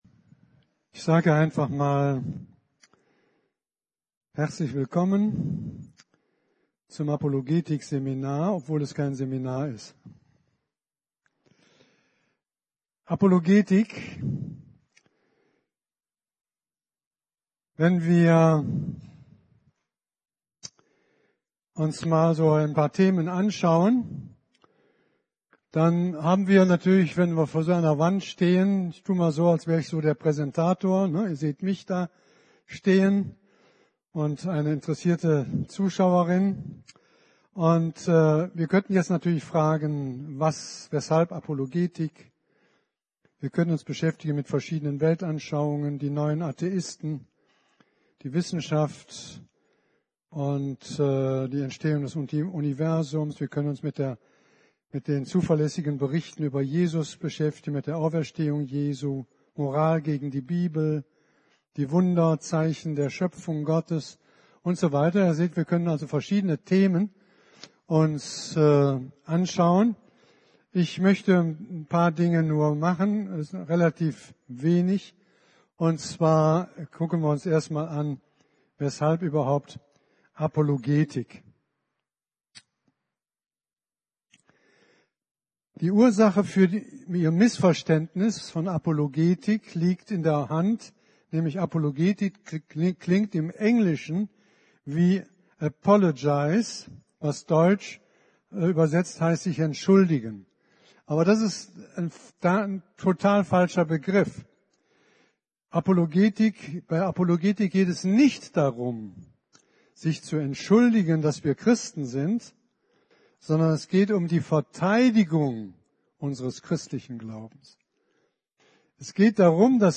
Predigten